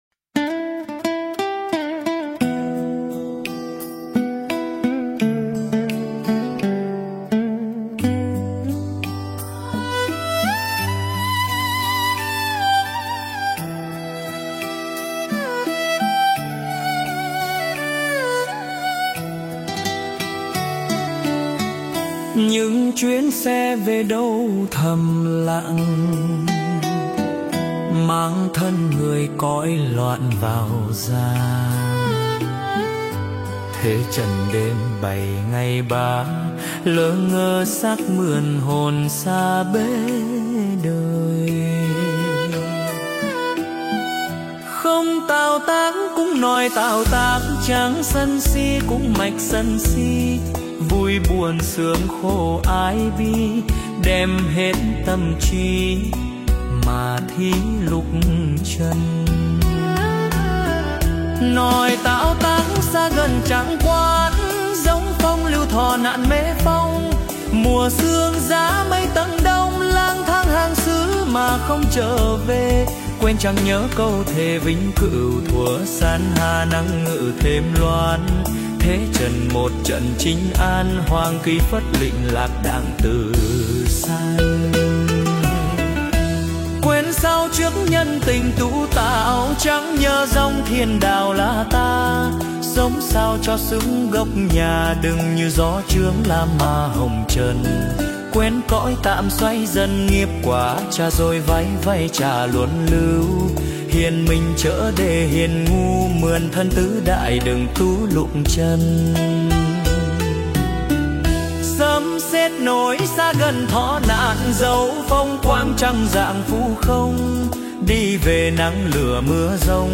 520. Nhạc thiền (số 03) - Vị Lai Pháp